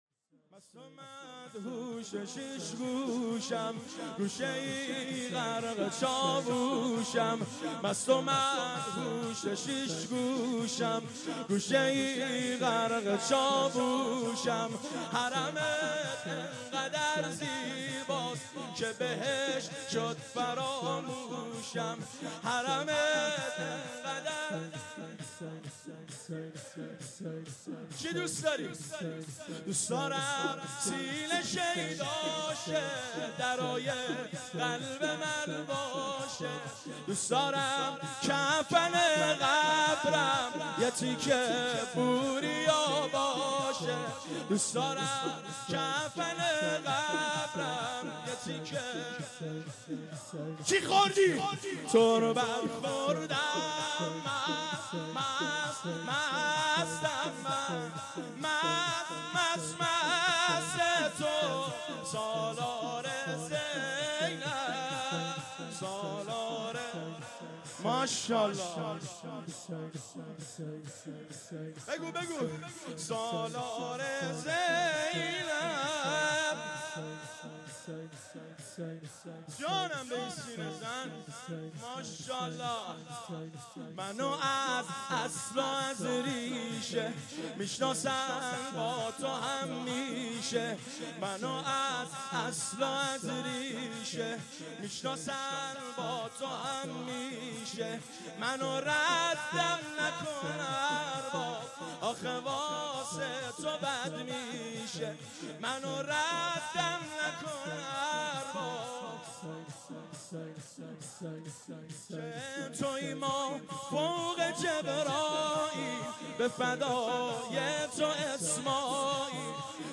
من فقیرم،من ندارم،مستمندم پیش تو عرض حاجت می کنم ( شور )
برای دانلود مداحی بر روی لینک ها کلیک کنید